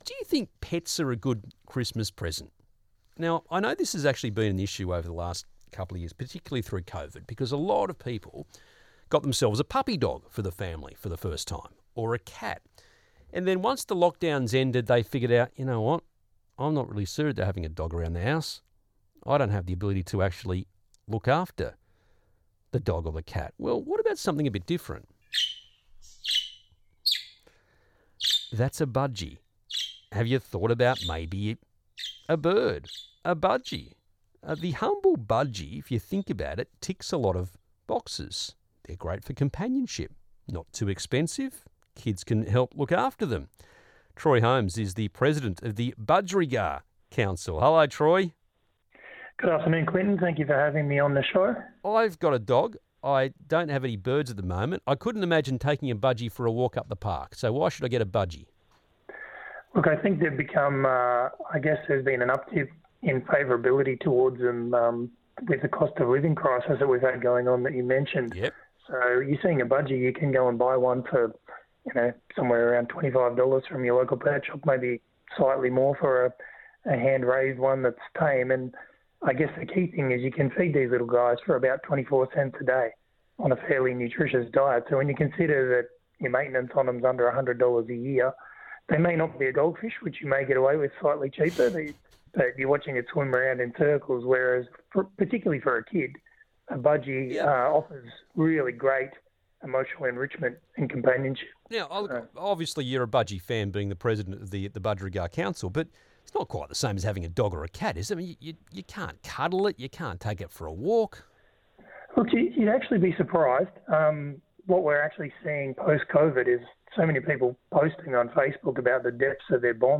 Budgies: The Perfect Christmas Gift – Interview Summary